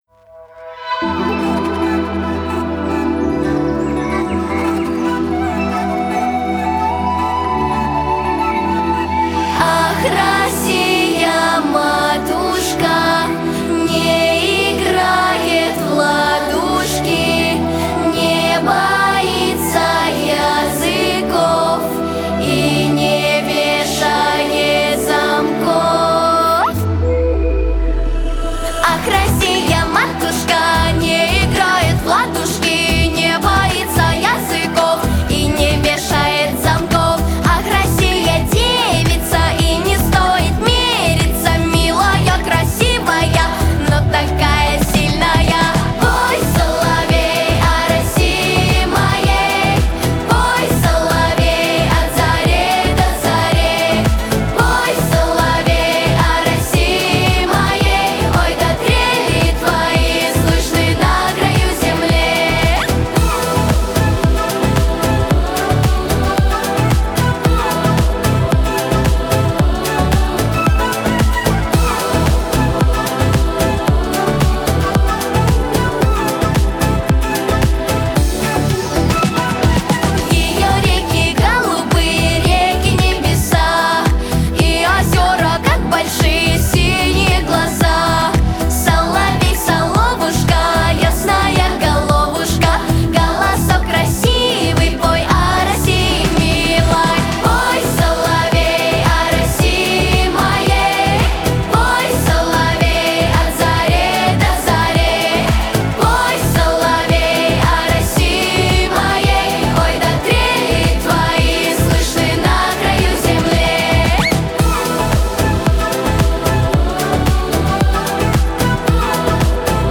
• Жанр: Детские песни
народный мотив